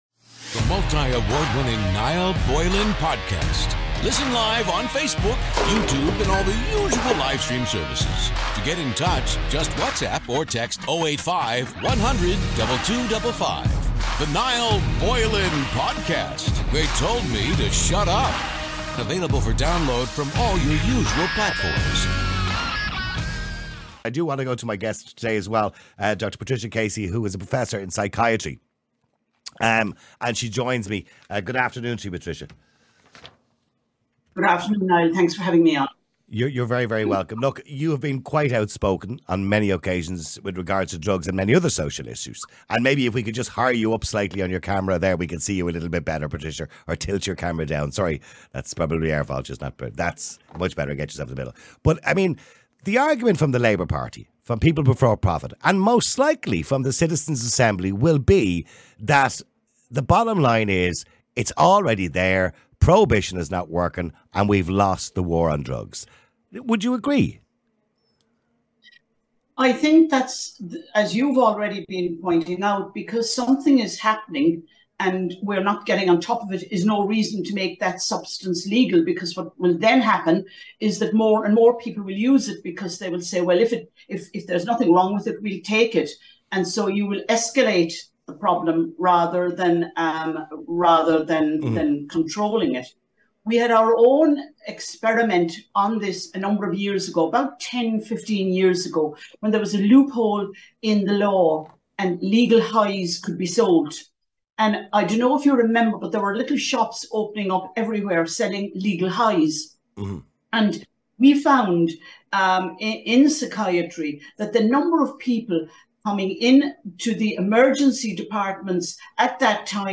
#15 Have We Lost The War On Drugs? (Interview only) – My CMS